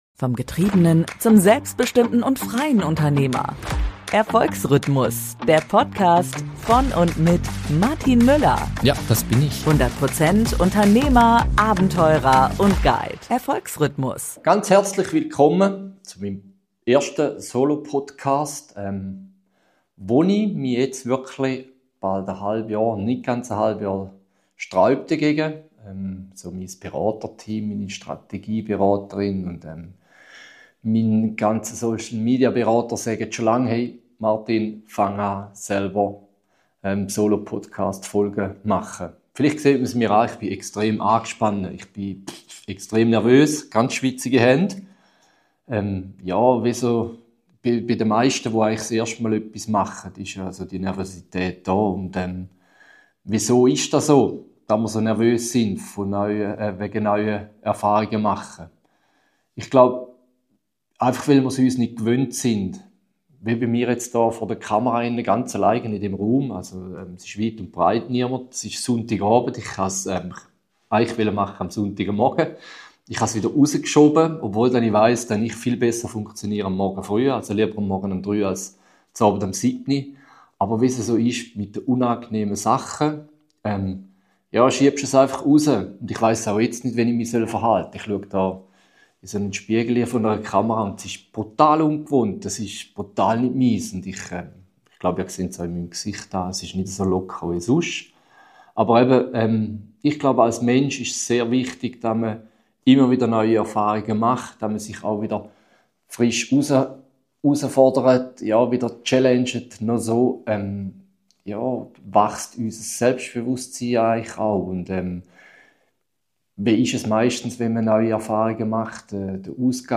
In meiner ersten Solo-Podcast-Folge spreche ich über die Kraft neuer Erfahrungen und warum sie essenziell für persönliches Wachstum und Erfolg sind.